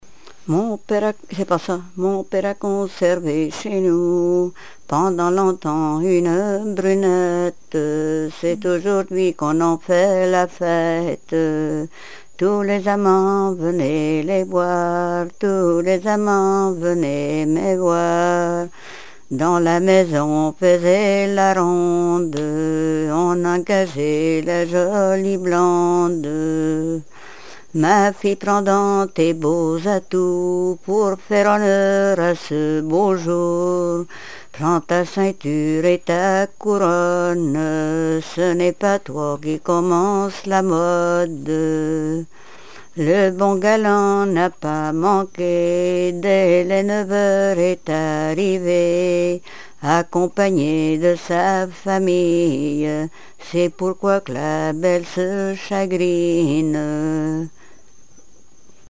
Notre-Dame-de-Monts
Genre strophique
Répertoire de chansons traditionnelles et populaires
Pièce musicale inédite